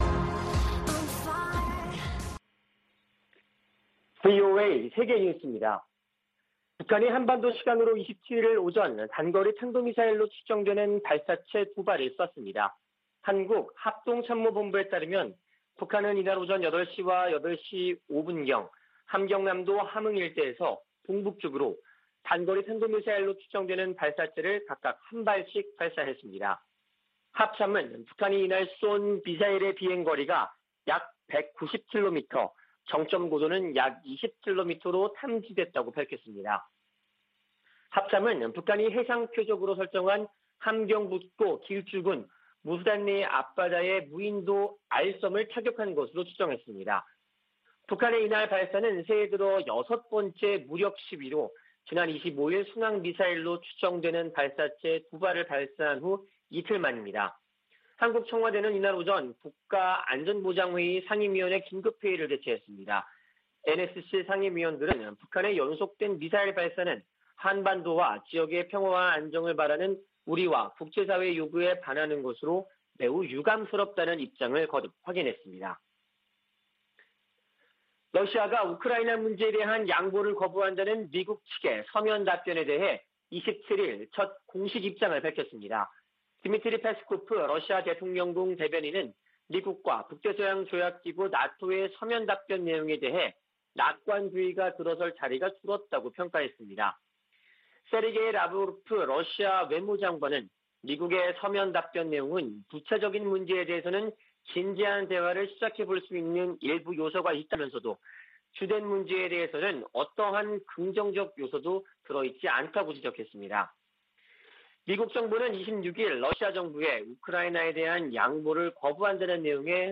VOA 한국어 아침 뉴스 프로그램 '워싱턴 뉴스 광장' 2021년 1월 28일 방송입니다. 북한이 또 단거리 탄도미사일로 추정되는 발사체 2발을 동해상으로 쐈습니다.